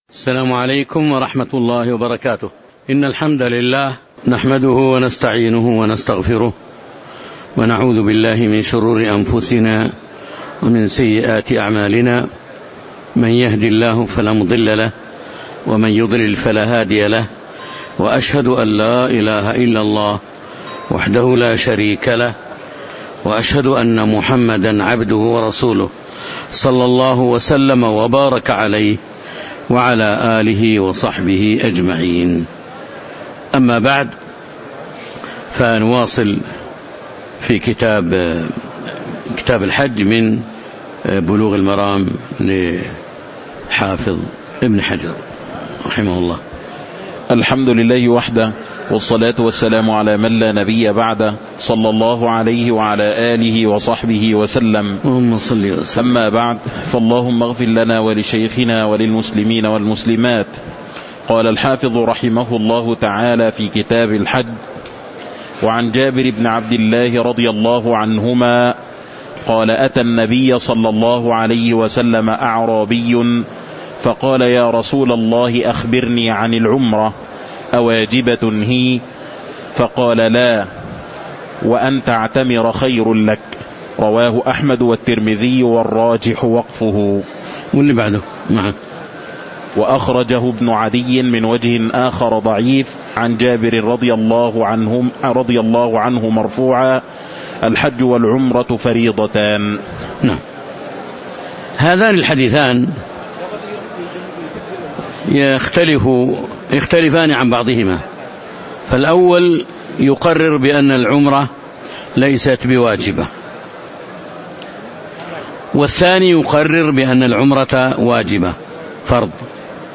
تاريخ النشر ٢١ ذو القعدة ١٤٤٦ هـ المكان: المسجد النبوي الشيخ